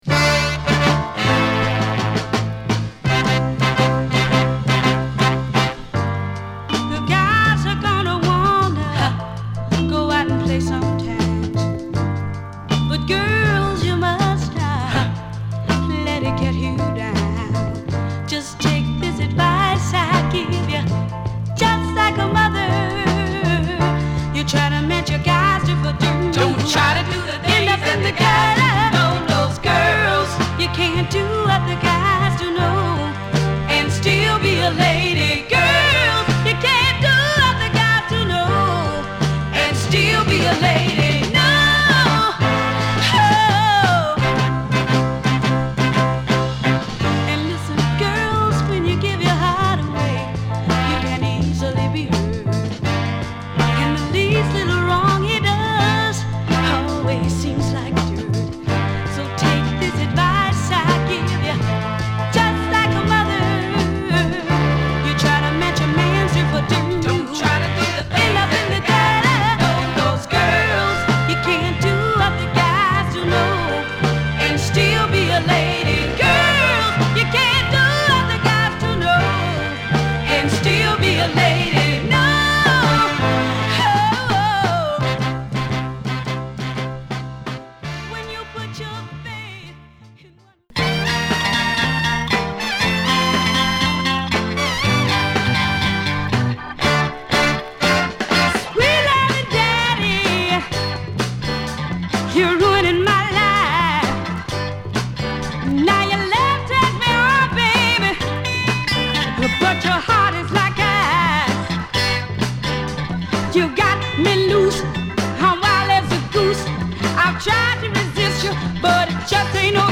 7inch
マイアミらしいメロウネスを持ちながらも爽やかに展開するグッド・ソウル！